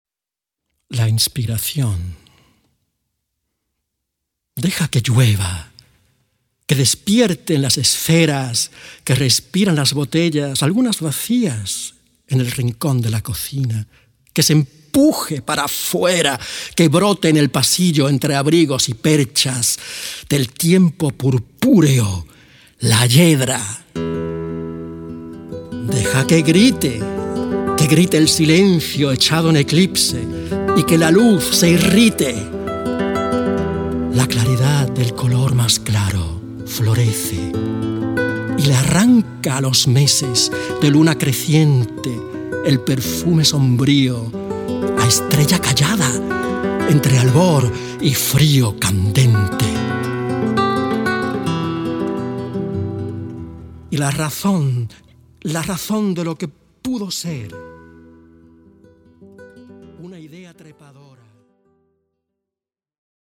Lyrik & Spanische Gitarre
Ein außergewöhnliches Zusammenspiel von Klang und Wort.